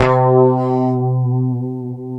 C#3 HSTRT VB.wav